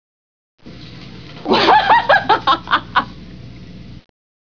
Julia Roberts Laughing
prettywomanlaugh.wav